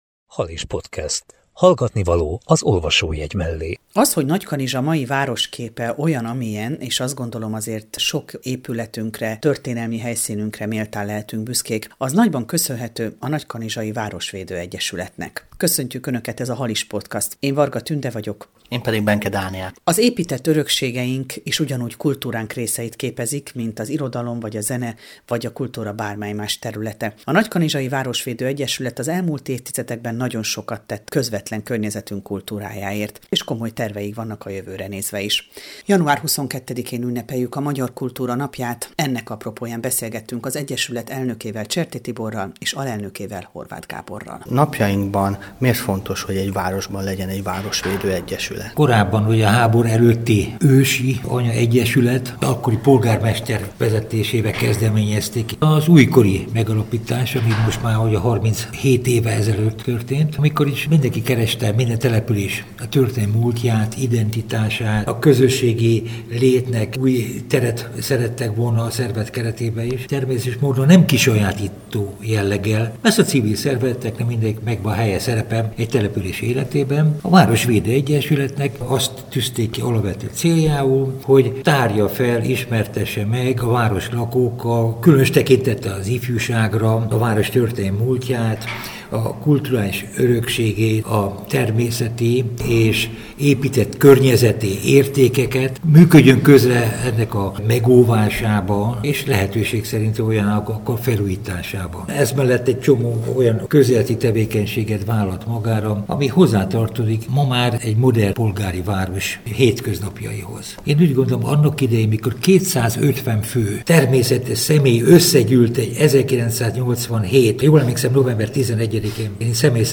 Halis Podcast 61 - Beszélgetés